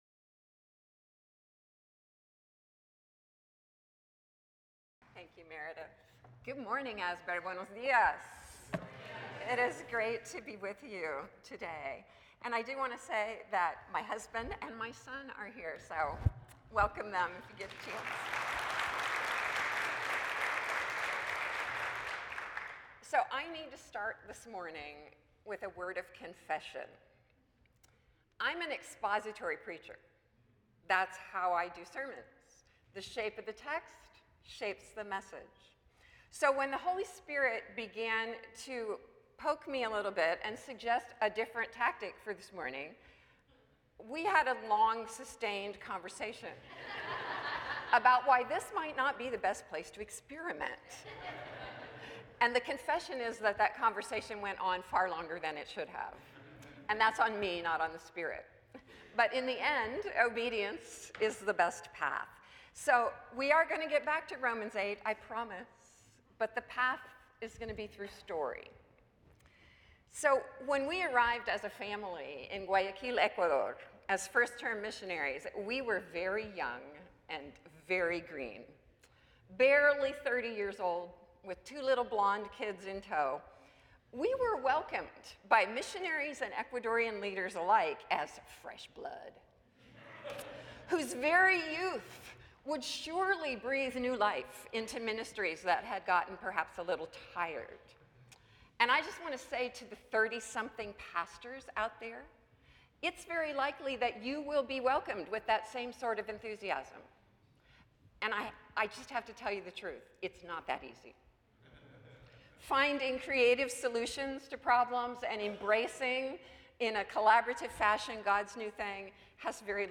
The following service took place on Thursday, March 19, 2026.